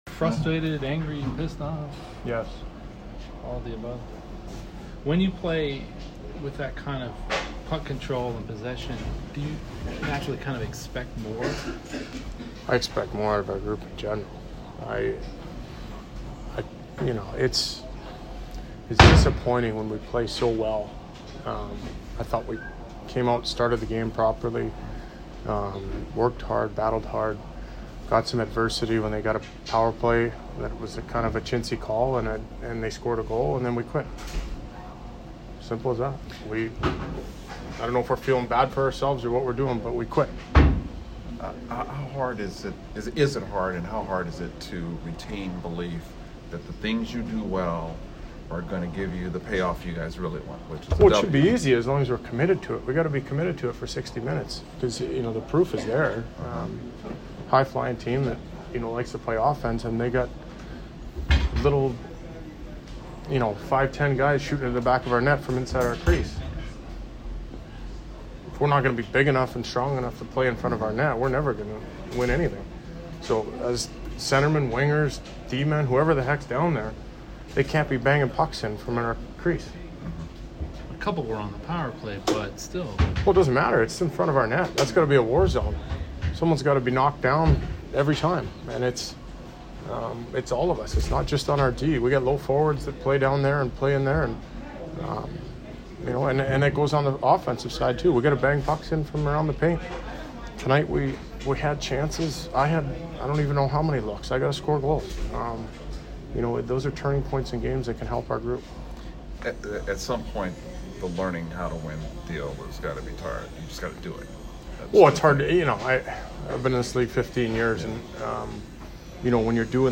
Ryan Getzlaf post-game 11/23